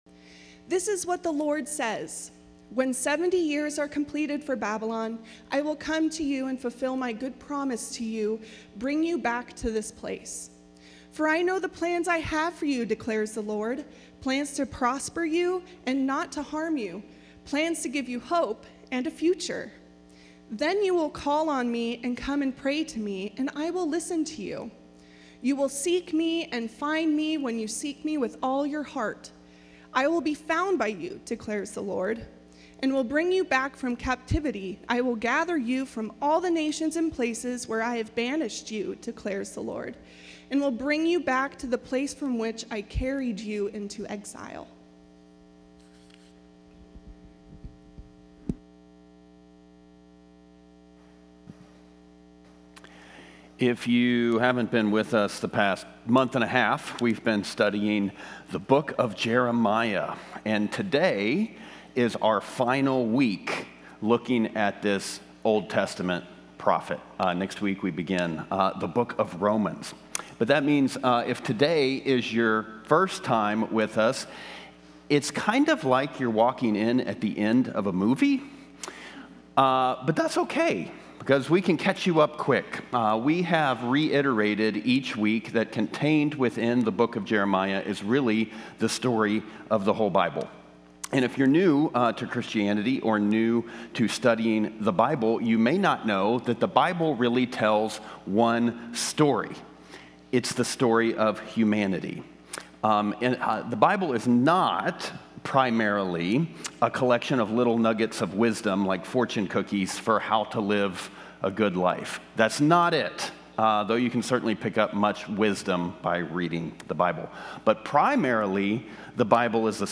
Online Service - Bridges Community Church Los Altos